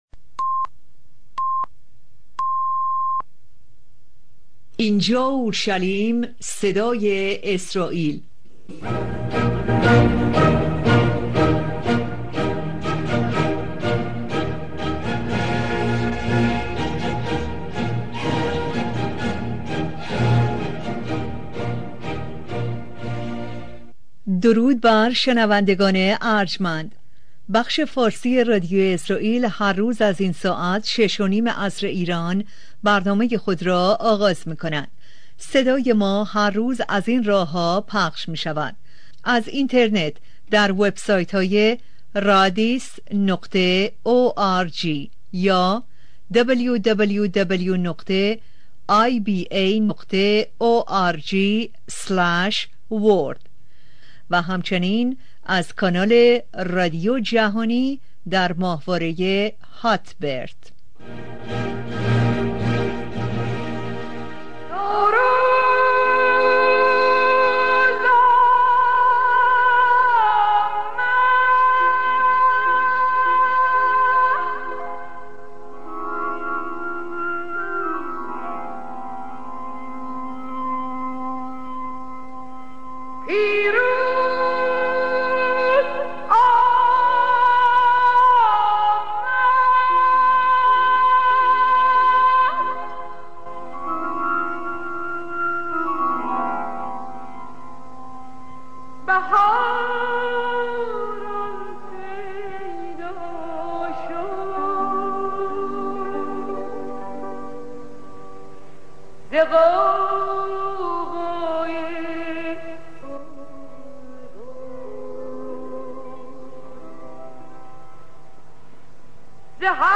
Listen to the Live Broadcast